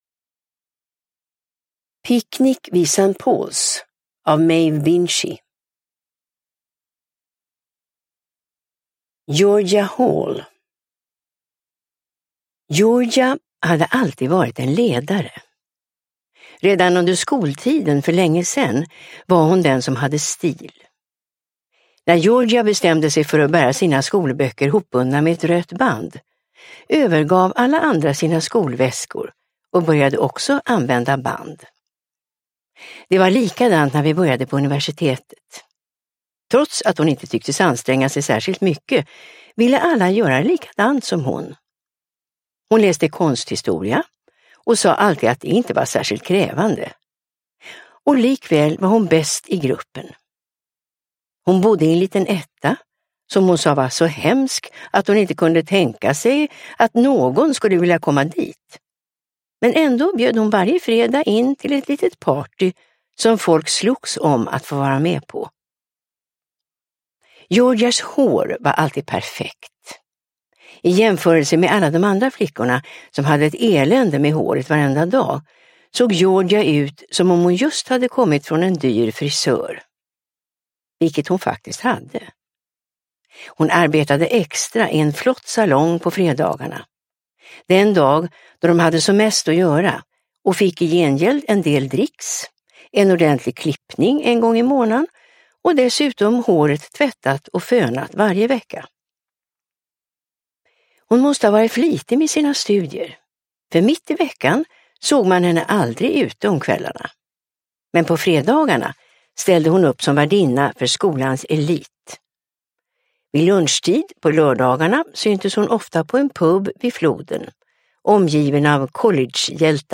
Picknick vid St Paul's – Ljudbok – Laddas ner